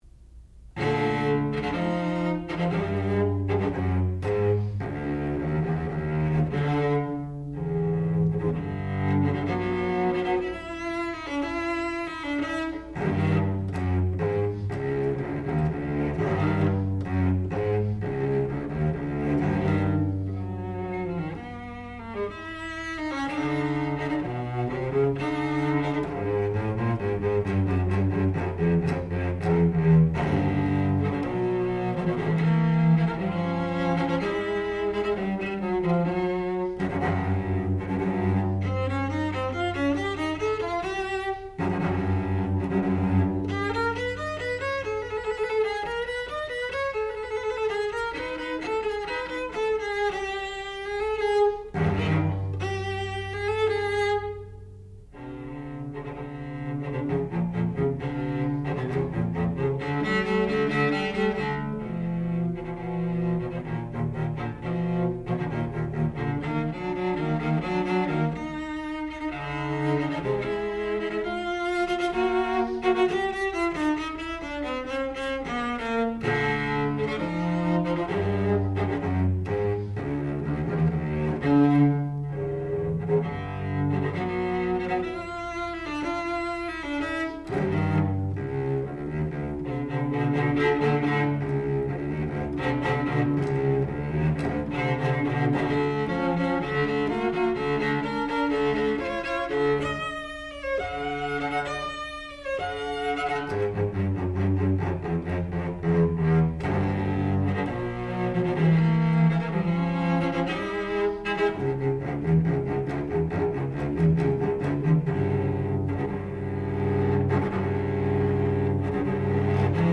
Sonata, op. 25, no. 3 for violoncello solo
classical music
Advanced recital